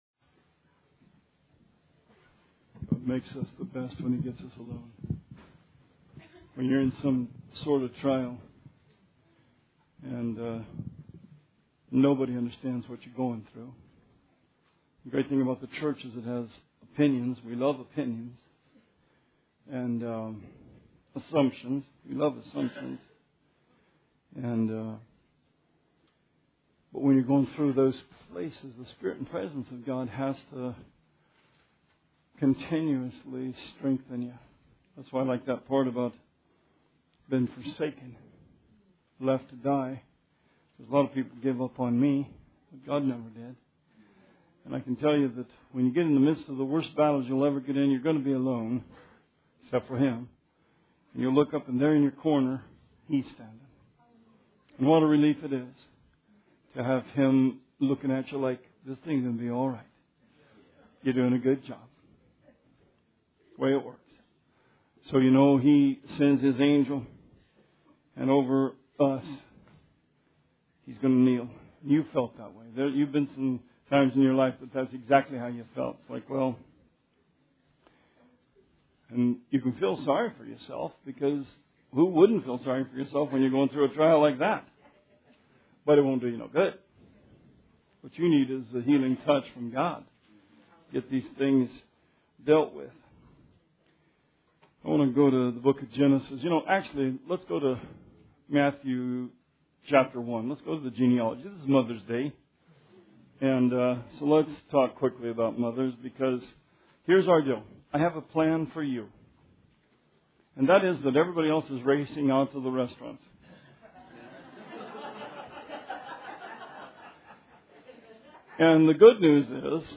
Sermon 5/8/16